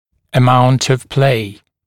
[ə’maunt əv pleɪ][э’маунт ов плэй]величина зазора (люфта) (напр. между прямоугольной дугой и пазом брекета)